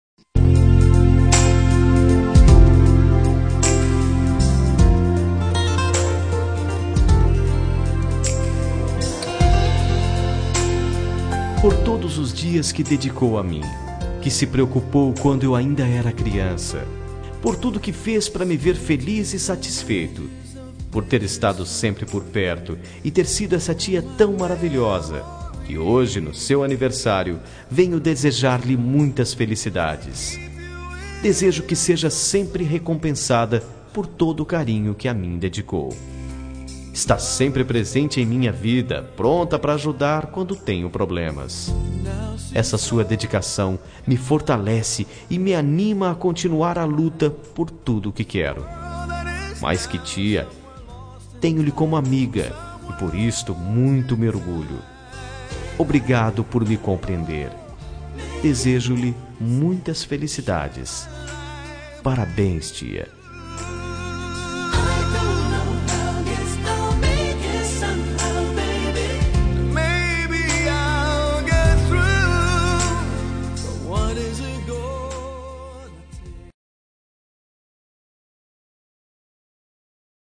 Telemensagem Aniversário de Tia – Voz Masculina – Cód: 2015